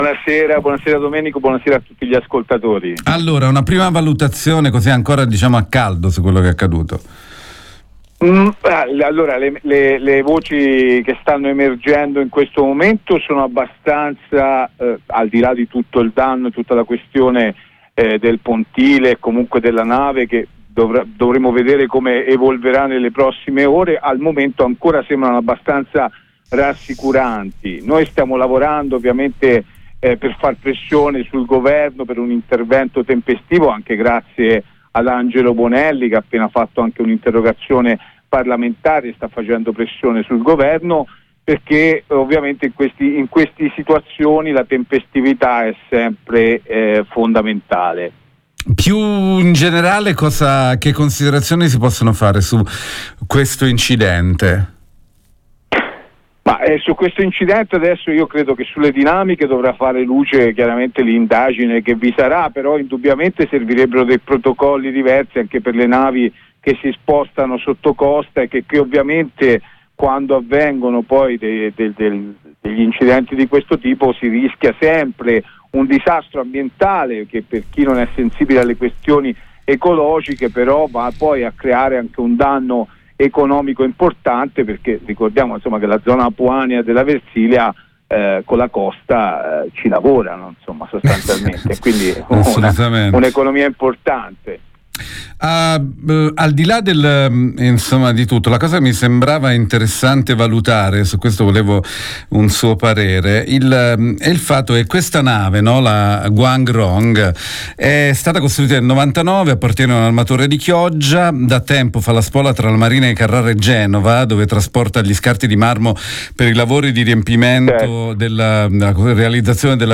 lo abbiamo intervistato.